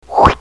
cartoon29.mp3